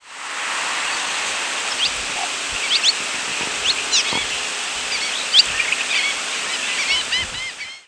Cave Swallow Petrochelidon pelodoma
Flight call description A descending call (a pure-toned "psuer" or a more husky "nhew") and a soft, rising call ("swheet" or "nhwit").
Rising  ("nhit") call from bird of mainland population (P. p. pelodoma) in flight.